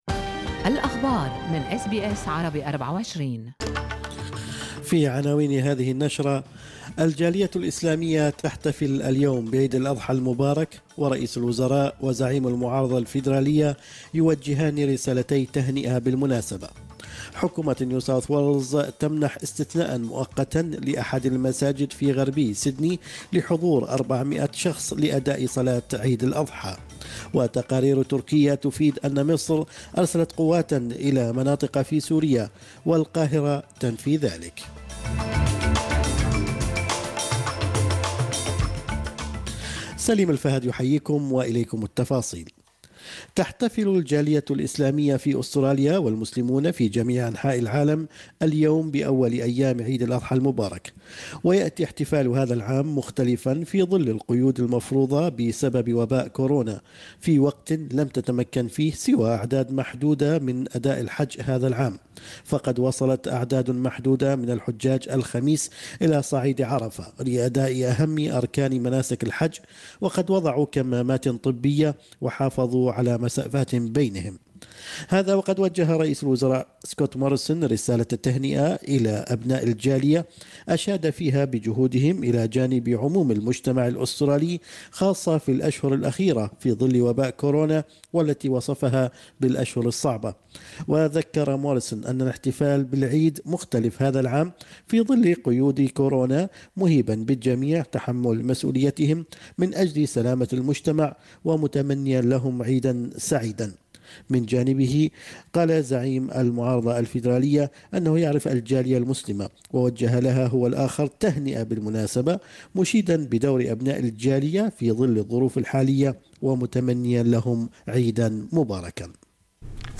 نشرة أخبار الصباح 31/7/2020